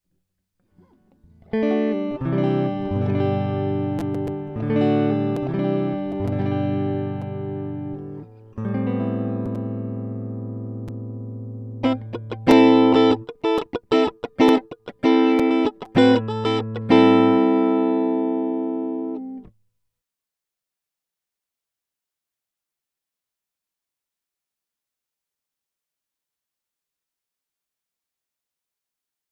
Il y avait cette p... de gaine noire intérieure qui touchait le point chaud.Après rectification c'est mieux
mais moins bien que le premier quand même... mais pas de grand chose...
Perso je trouve le 3è toujours beaucoup moins bon même après rectification.
J'ai l'impression que l'attaque était beaucoup moins forte aussi à la prise mais il n'y a pas que ça, le son est comme plus lointain, moins clair et punchy. Moins de volume aussi (dû à l'attaque ?)